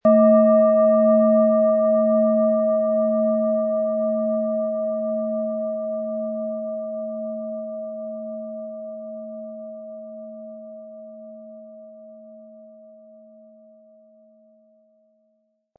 • Mittlerer Ton: Alphawelle
Lassen Sie die Klangschale mit dem kostenlosen Klöppel sanft erklingen und erfreuen Sie sich an der wohltuenden Wirkung Ihrer Biorhythmus Seele.